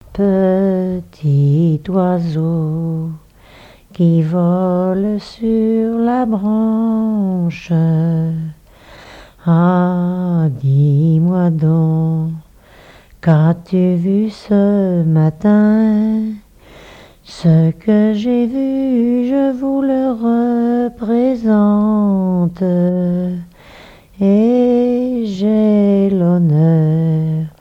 Genre strophique
collecte en Vendée
répertoire de chansons de noces
Pièce musicale inédite